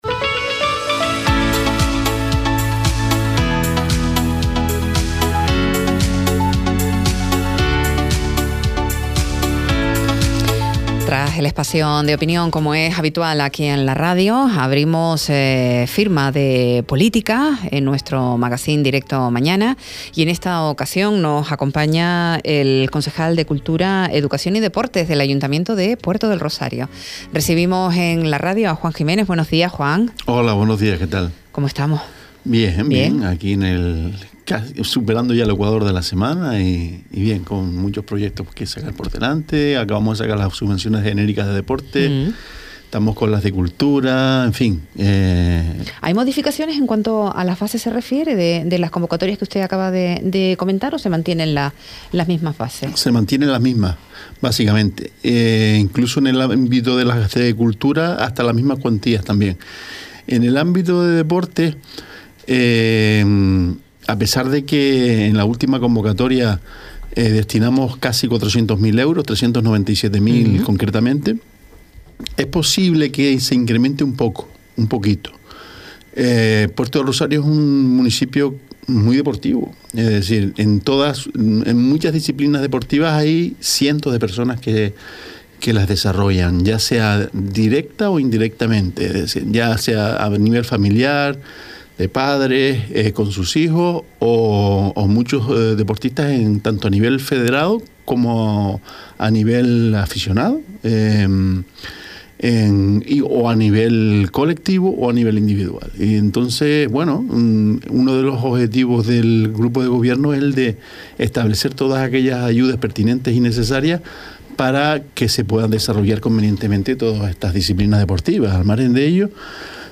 Juan Jiménez González concejal de Educación, Cultura, Deportes y Transporte ofreció hoy en la Radio Sintonía los detalles de la convocatoria de subvenciones en materia deportiva
Entrevistas